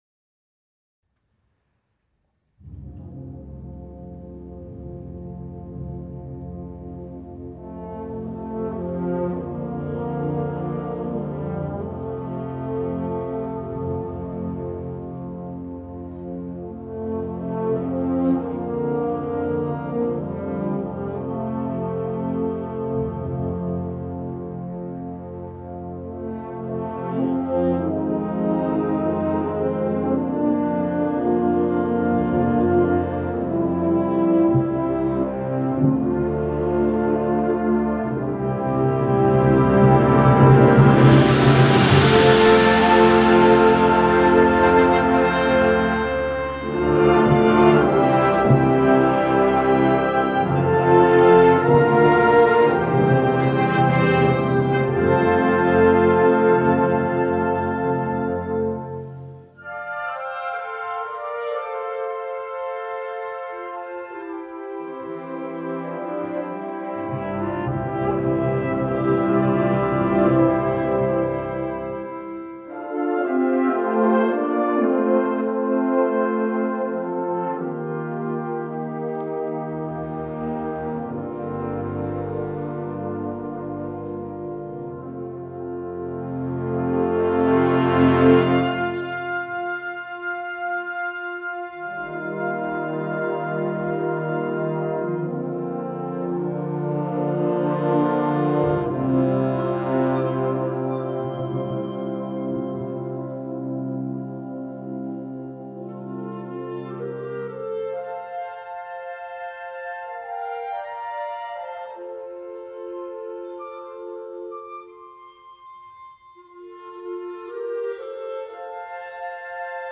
Gattung: Konzertwerk für Blasorchester
Besetzung: Blasorchester